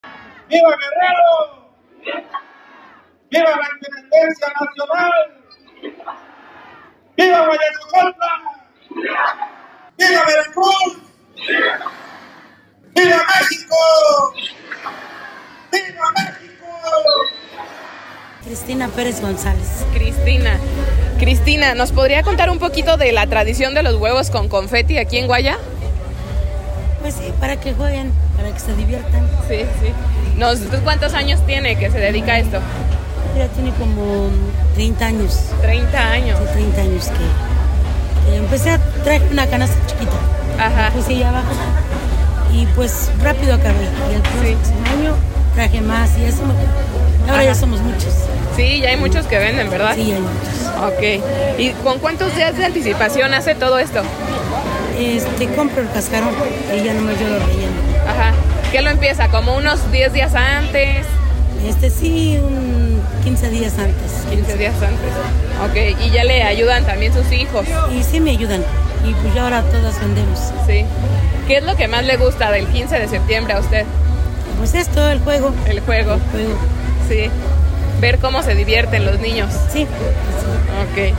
Les presentamos la voz desde Huayacocotla en esta fiesta patria.
GRito-en-Huaya.mp3